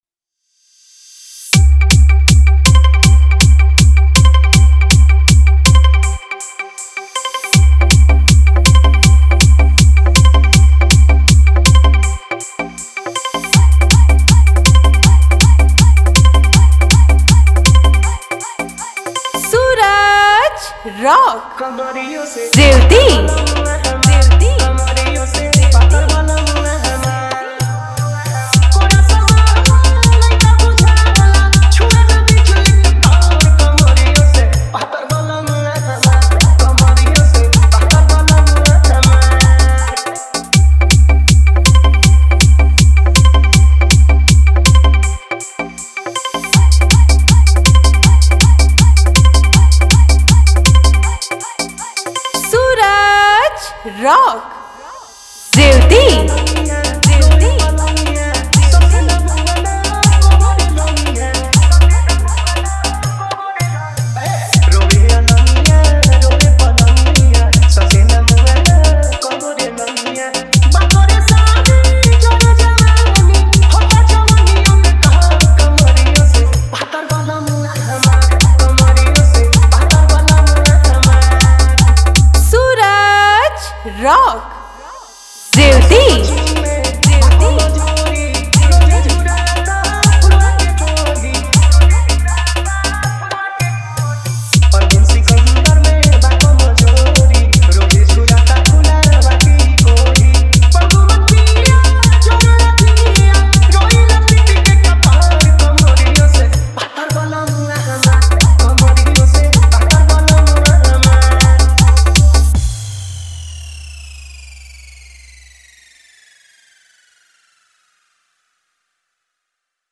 Upcoming: - 2025 Special Bhojpuri EDM Tahalka Song